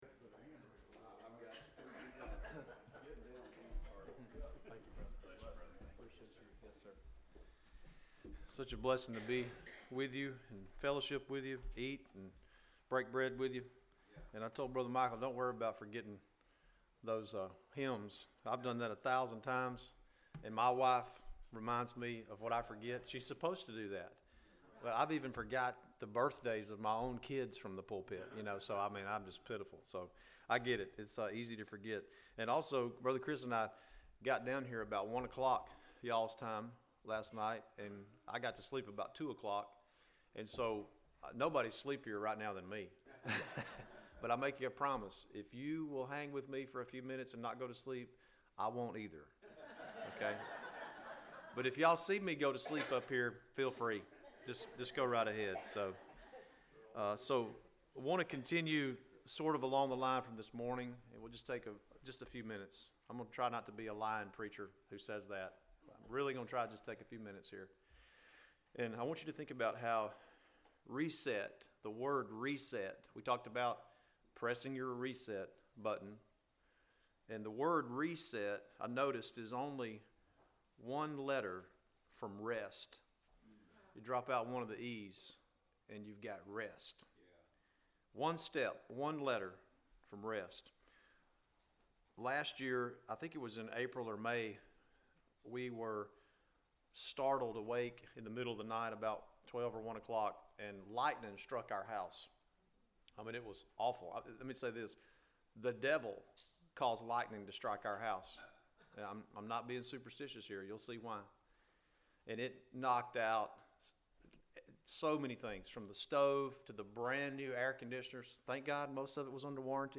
Service Type: Saturday Afternoon